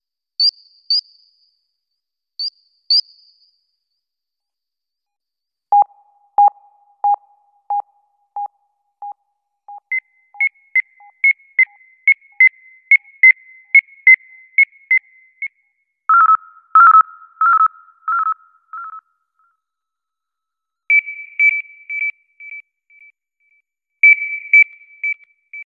Alarme